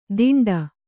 ♪ diṇḍa